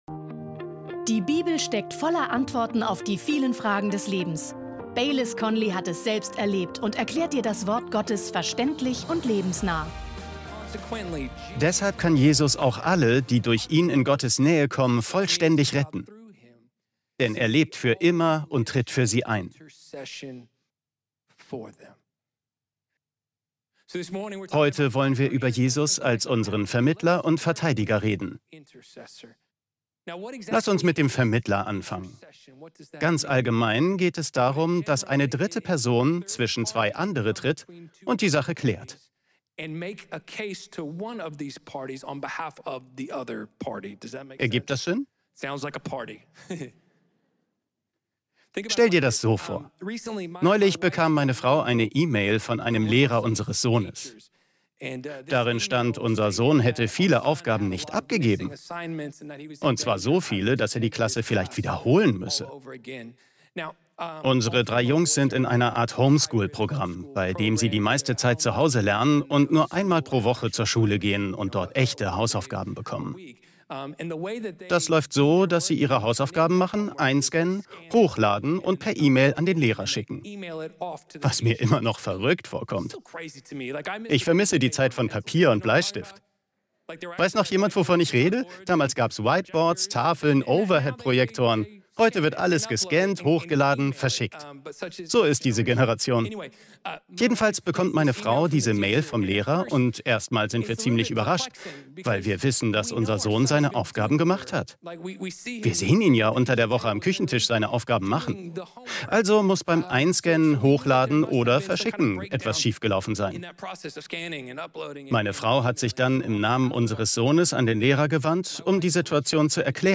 In dieser fesselnden Predigt erfährst du, wie Jesus als dein persönlicher Vermittler und Verteidiger handelt.